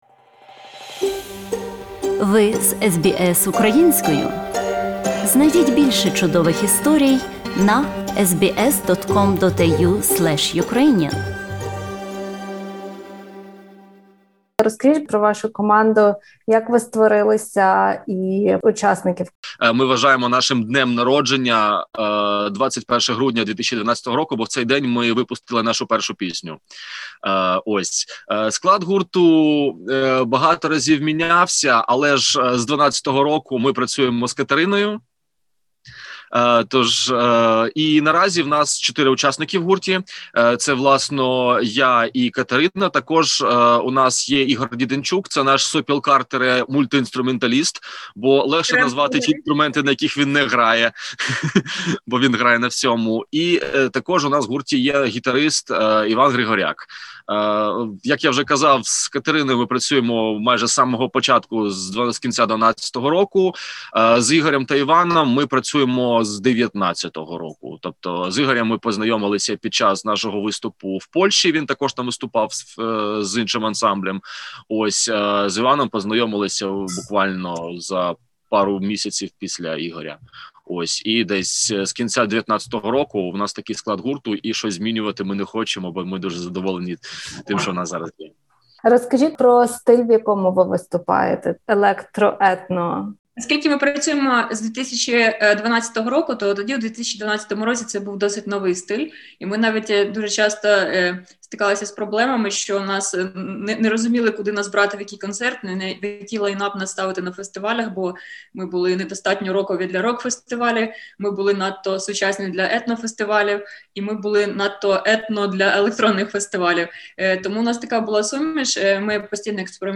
Український гурт Go_A, у розмові з SBS Українською, розповів про те, як потрапили на Євробачення, що хочуть донести світові, про Чорнобиль та коронавірус і відповідальність виступу на конкурсі після перемог Руслани та Джамали.